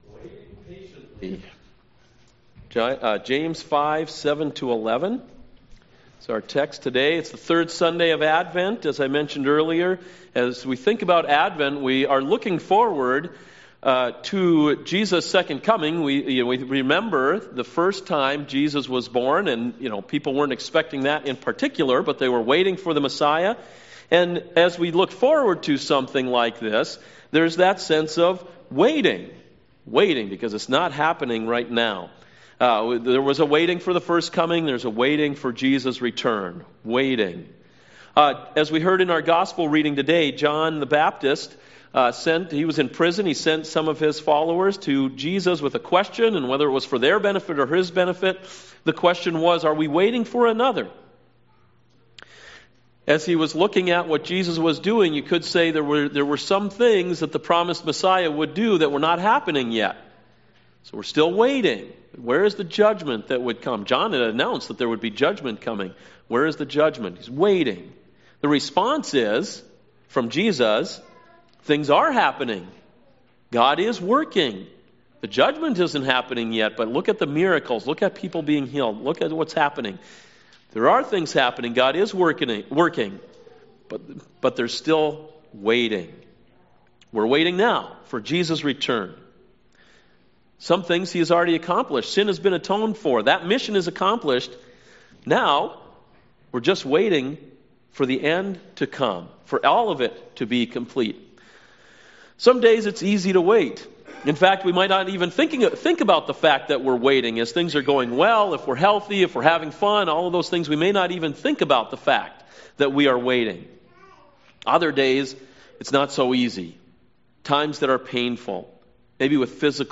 CoJ Sermons Waiting Patiently (James 5:7-11)